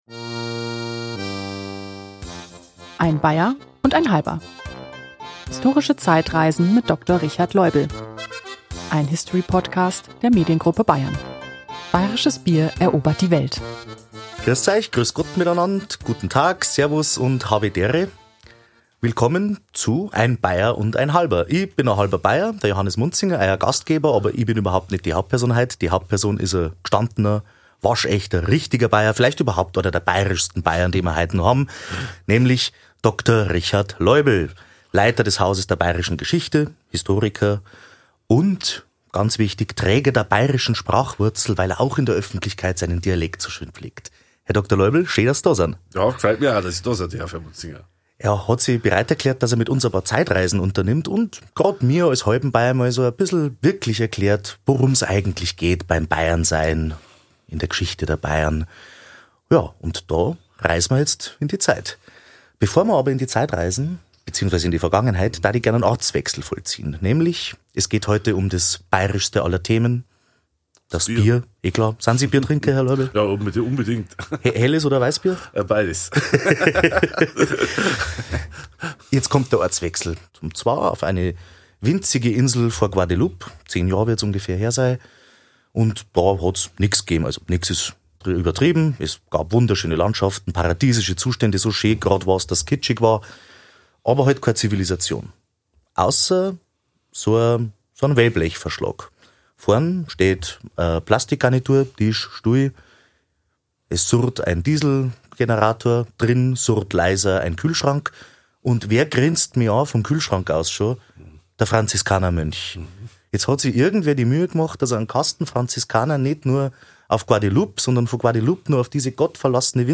Sein Niederbairisch pflegt er in der Öffentlichkeit mit solcher Hingabe, dass er dafür mit der „Bairischen Sprachwurzel“ ausgezeichnet wurde.